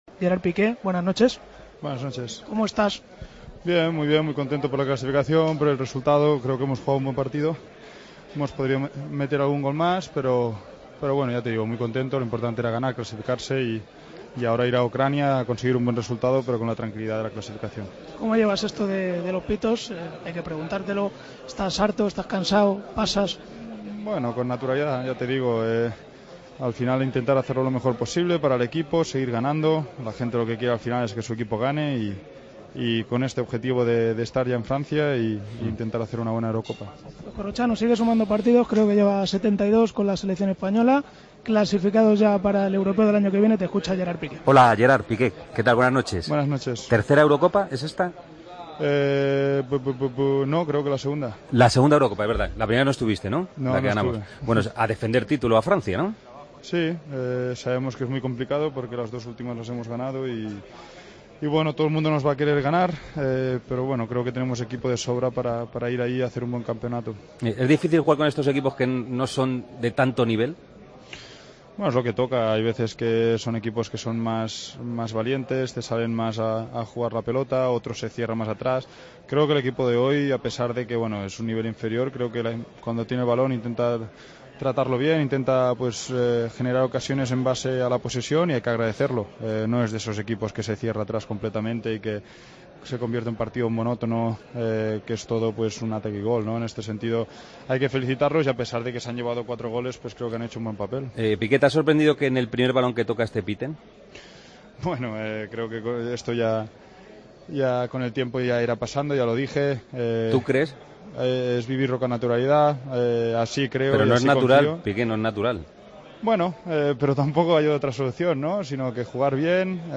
El central del Barcelona pasó por El Partido de las 12 tras el triunfo ante Luxemburgo.